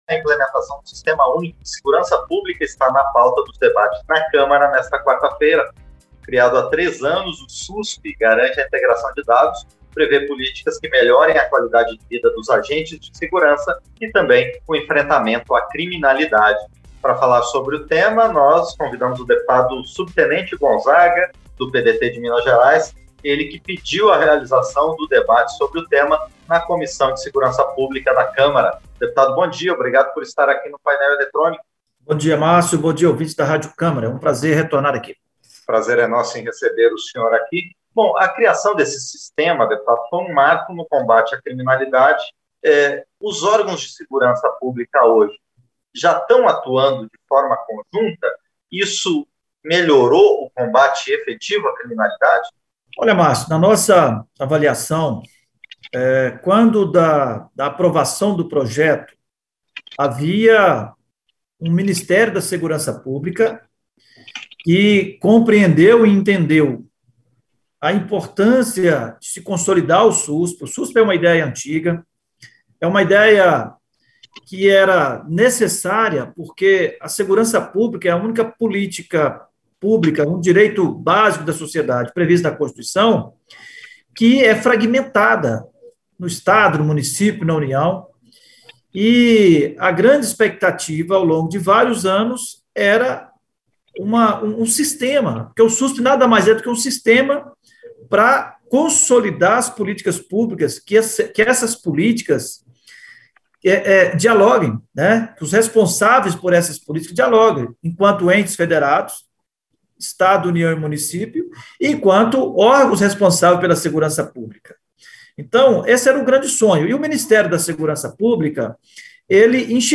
Entrevista - Dep.